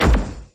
Scroll_Stop_Sound.mp3